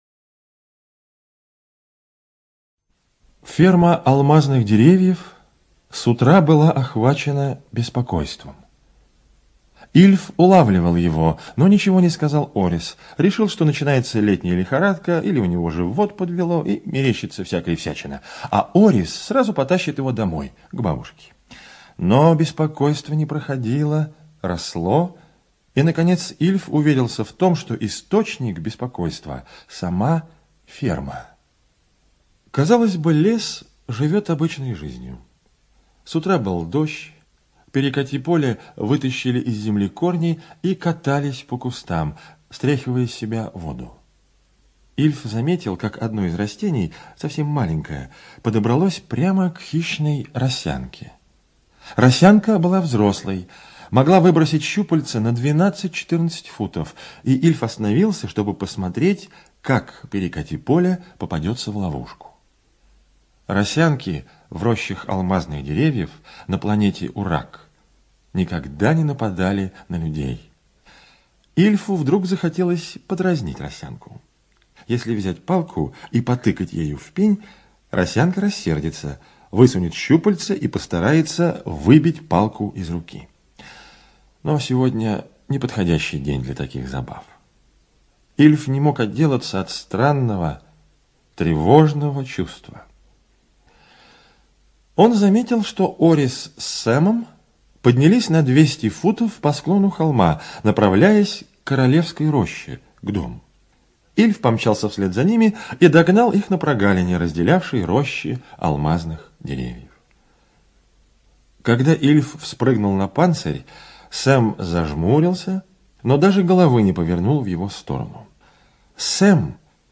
ЧитаетКиндинов Е.